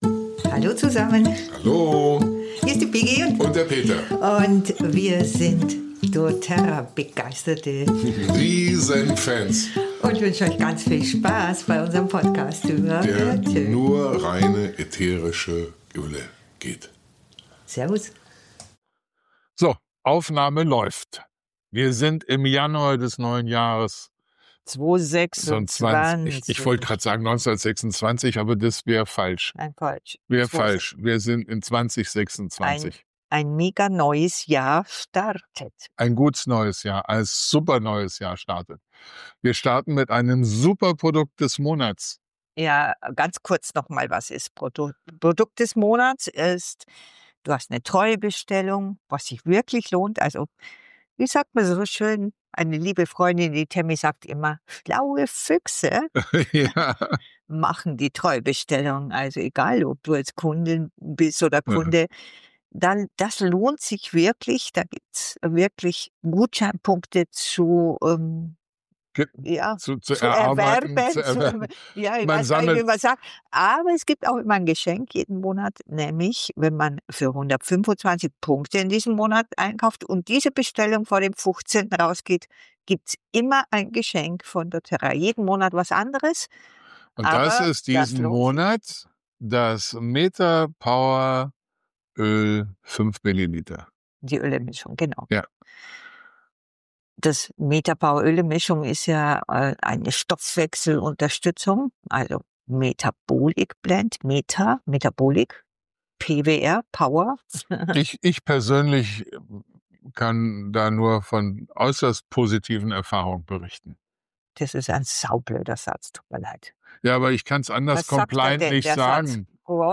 Deshalb hört ihr uns genau so wie wir sind. Auch wenn wir mal stolpern :) Mehr